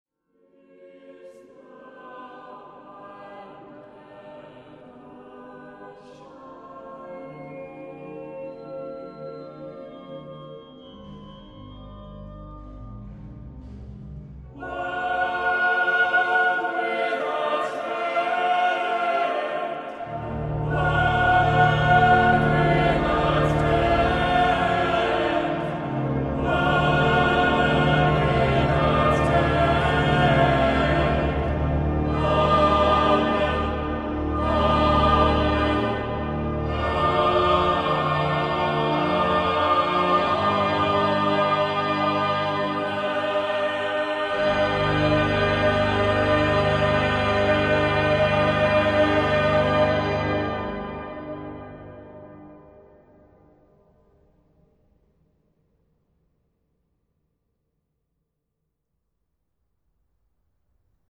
Sacred Choral Music
Recording made in Arundel Cathedral, 6th & 7th January 2001.